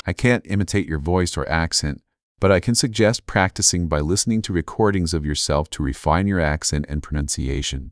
role0_VacuumCleaner_1.wav